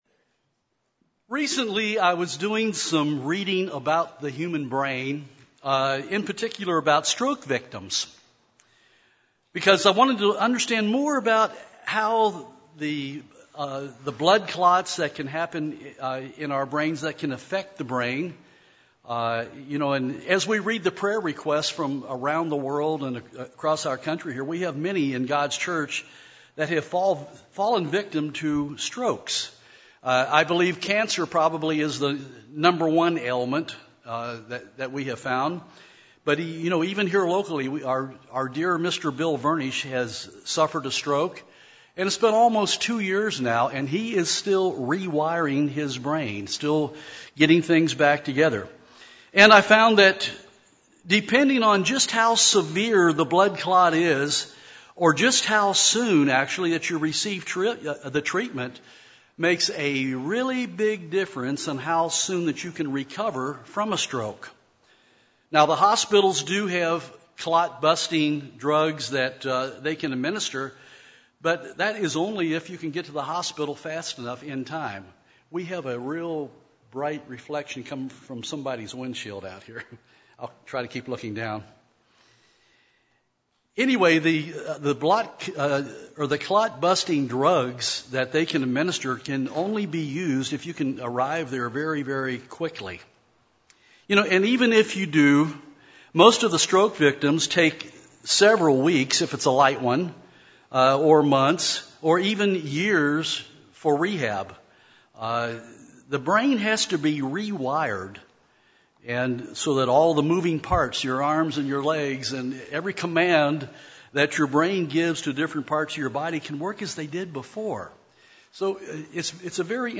This sermon was given at the Cincinnati, Ohio 2016 Feast site.